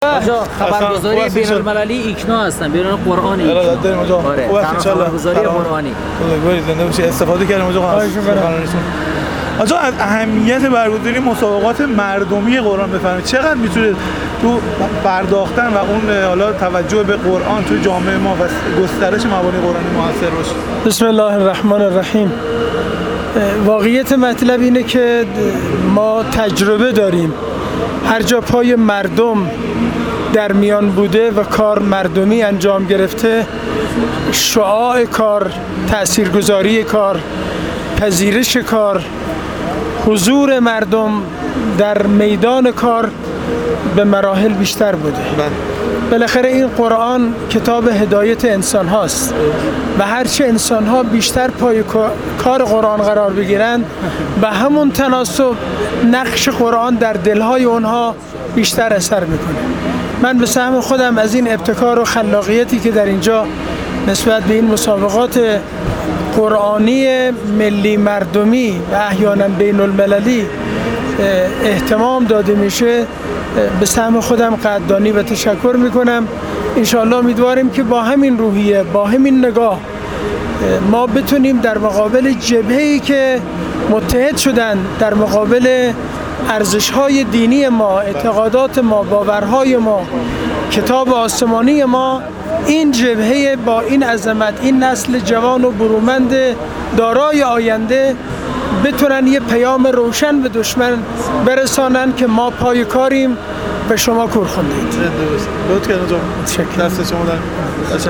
آیت‌الله سیدهاشم حسینی‌بوشهری، رئیس جامعه مدرسین حوزه علمیه قم در حاشیه اختتامیه مسابقات ملی مردمی قرآن مشکات، در گفت‌وگو با ایکنا، درباره اهمیت و جایگاه مسابقات و فعالیت‌های قرآنی مردمی و اثر اینگونه فعالیت‌های در توسعه و ترویج مبانی قرآنی در جامعه گفت: تجربه نشان داده هرجا پای مردم در میان بوده و کار مردمی انجام گرفته شعاع، تأثیرگذاری و پذیرش کار به مراتب بیشتر بوده است.